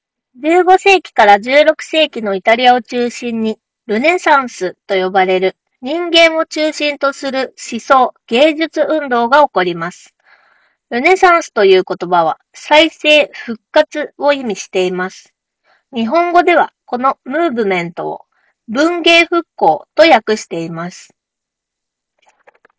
実際に録音に使ってみると、空調音や周囲の環境ノイズを効果的に抑えながら、装着者の声だけをしっかり拾い上げてくれました。
▼HUAWEI FreeClip 2のマイクで拾った音声単体
録音した音声を確認すると、わずかにくぐもった感じはあるものの、聞き取りづらさはなく、実用面で気になるレベルではありません。むしろ声は十分クリアで、Web会議用途でも、専用マイクの代替として問題なく使えるクオリティだと感じました。
huawei-freeclip-2-review.wav